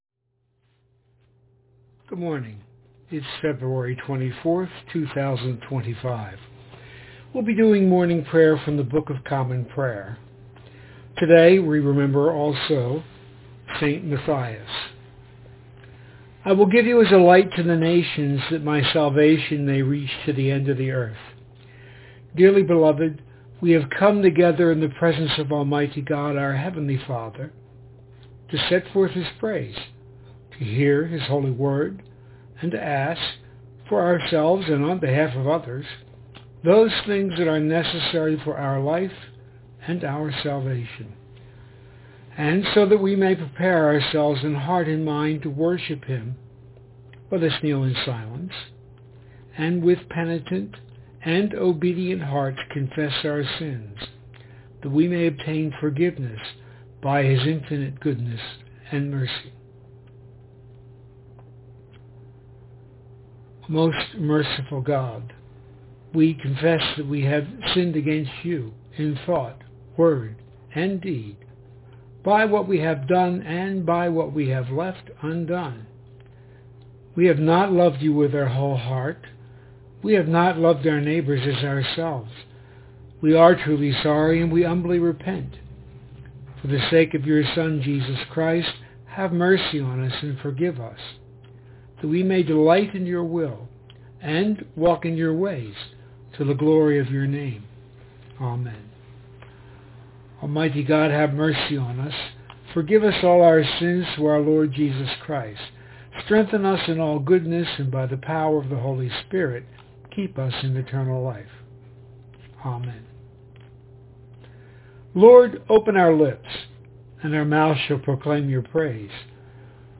Morning Prayer for Monday, 24 February 2025